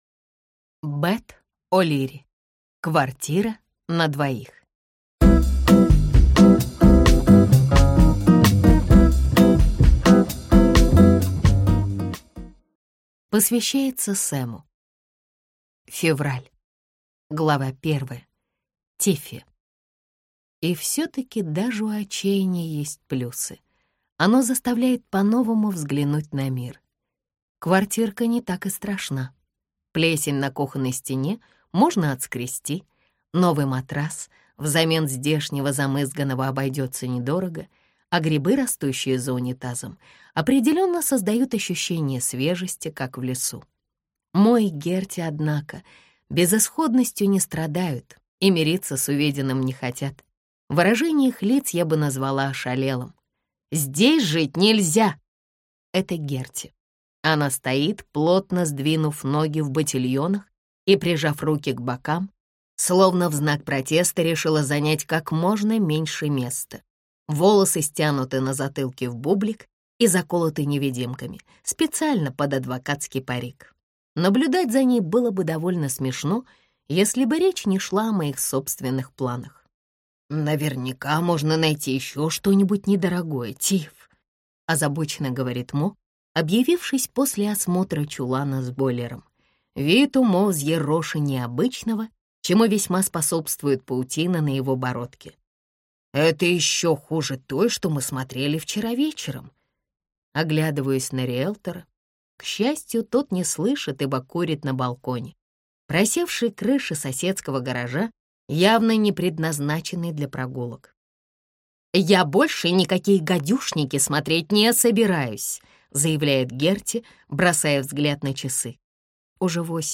Аудиокнига Квартира на двоих | Библиотека аудиокниг